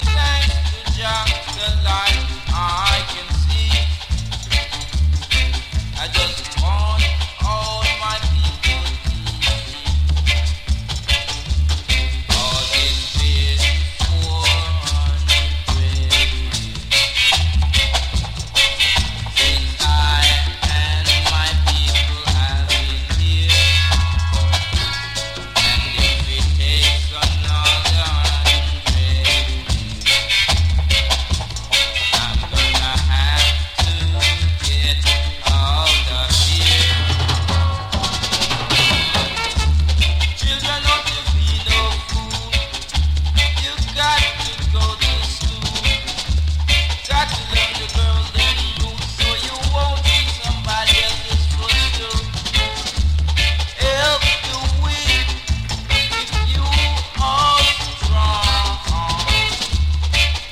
キラー・レアルーツ国内リイシュー